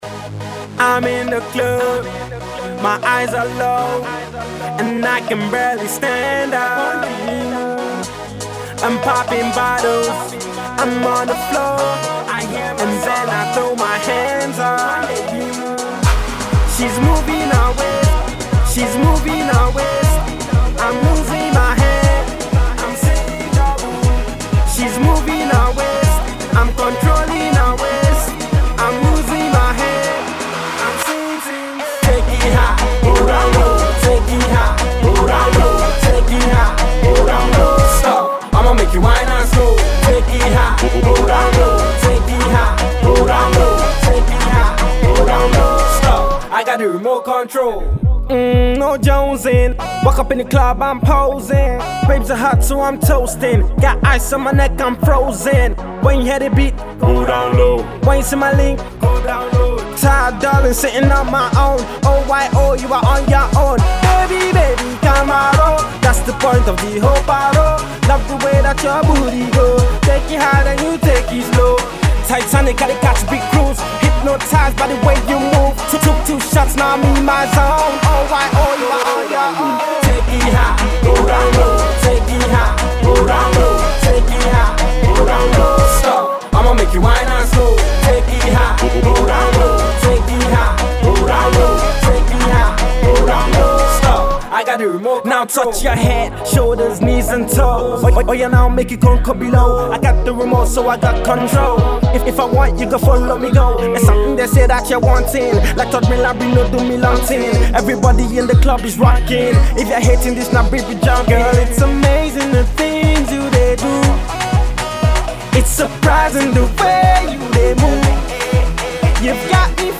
rapper
is definitely for the clubs